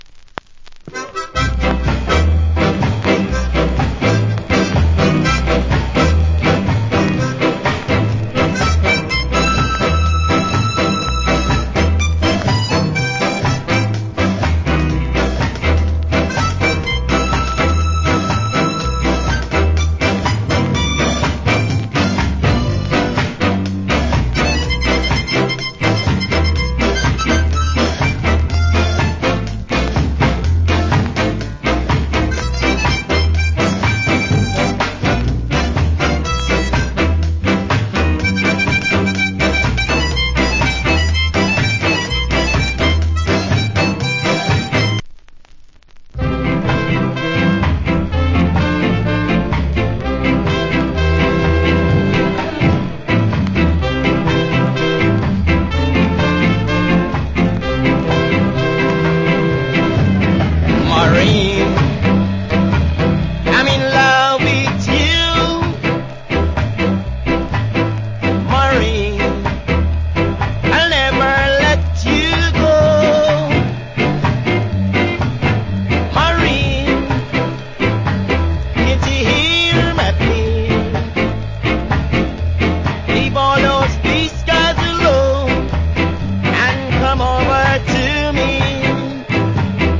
コメント Wicked Harmonica Ska Inst. / Good Ska Vocal.